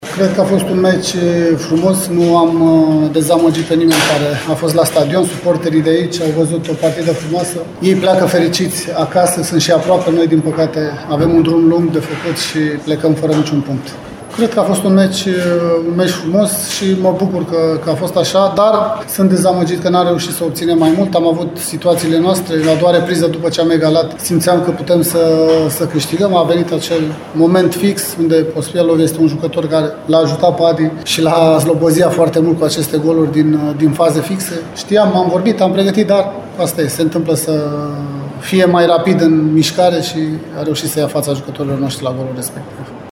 Antrenorul Farului, Ianis Zicu, e de părere că a fost un meci care a bucurat spectatorii. El regretă punctele pierdute tocmai pe faze pe care le anticipase în discuțiile cu echipa: